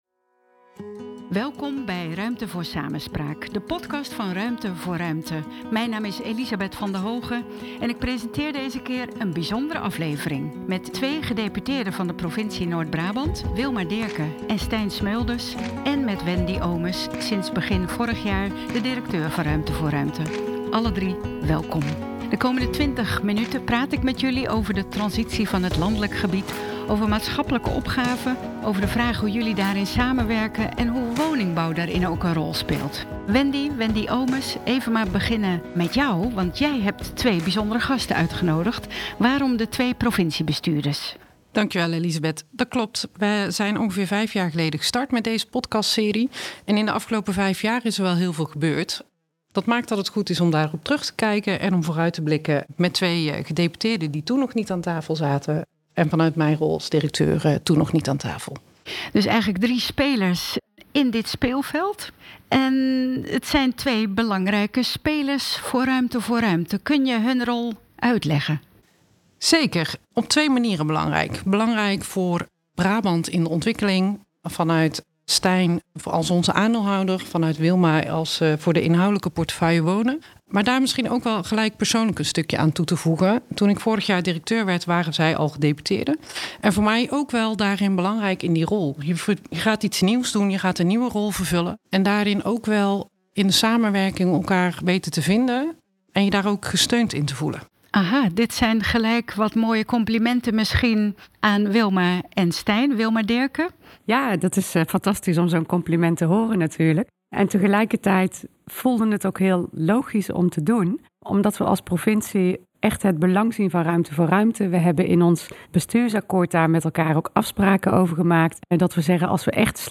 een bijzonder gesprek